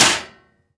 metal_impact_light_07.wav